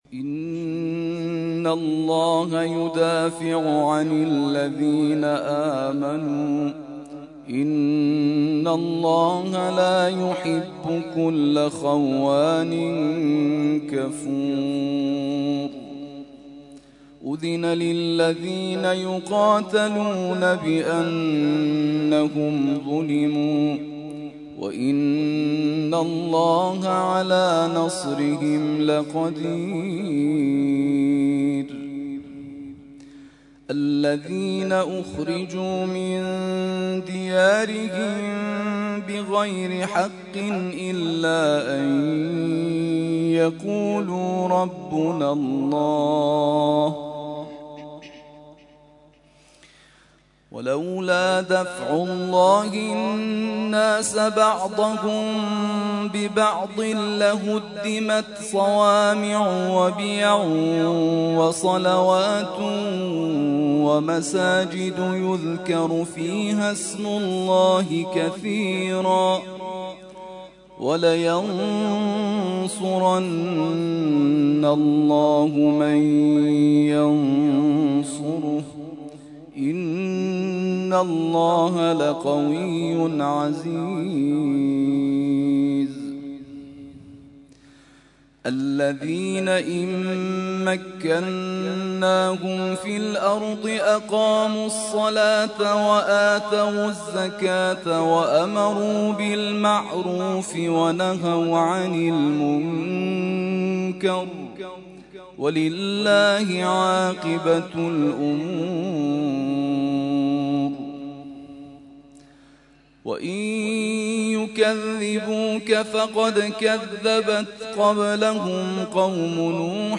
ترتیل خوانی جزء ۱۷ قرآن کریم در سال ۱۳۹۸